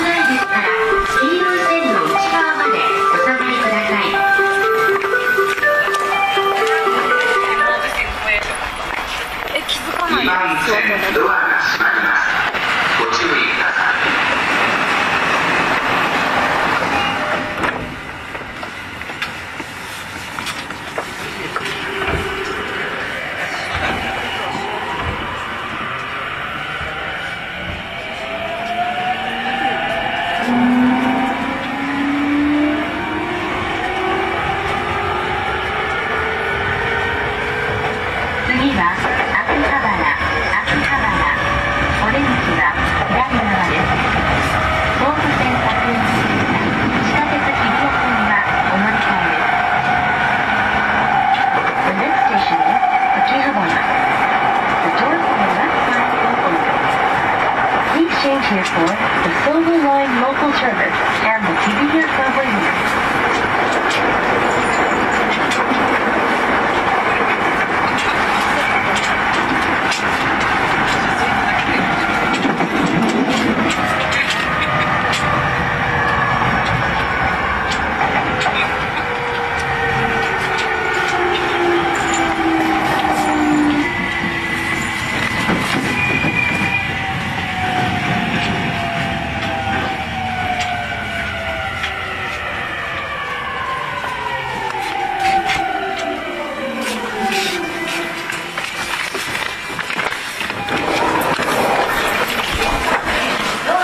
走行音
YM17 E231系 御徒町-秋葉原 1:43 9/10 --